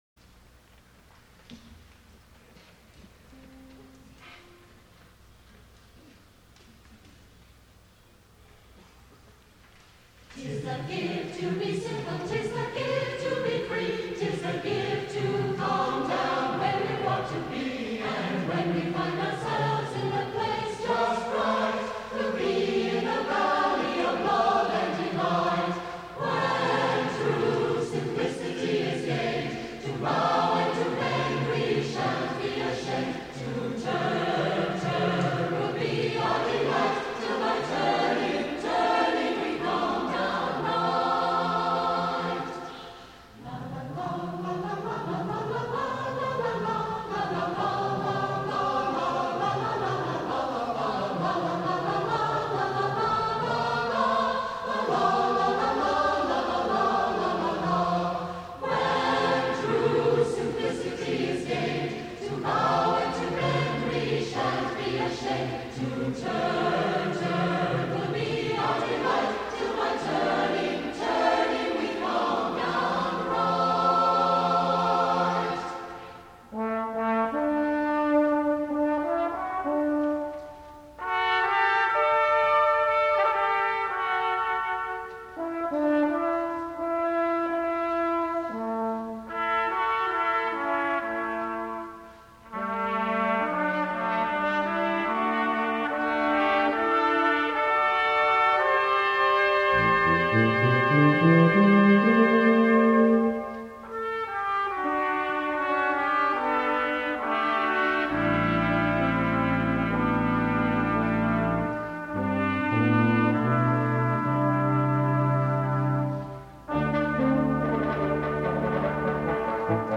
for Brass Quintet (1994)
with singing